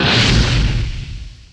Effects
Petrol.wav